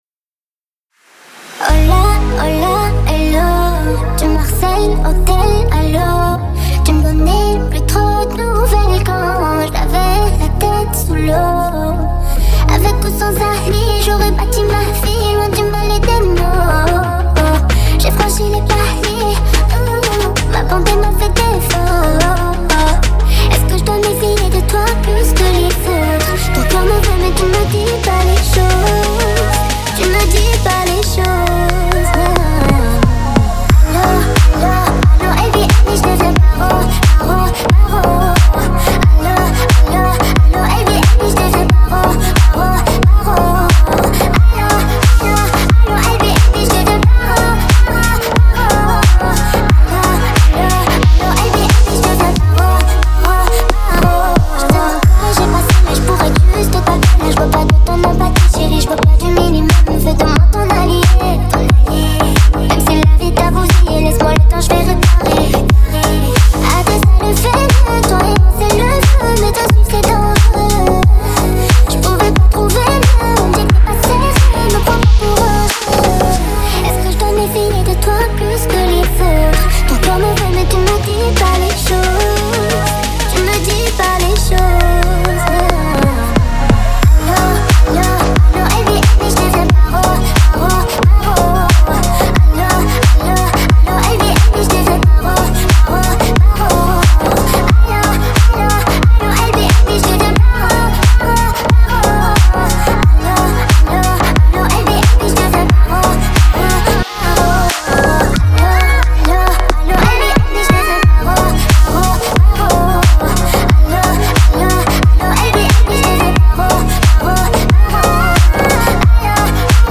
• Жанр: Pop, Dance